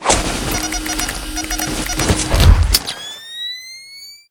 battlesuit_wear.ogg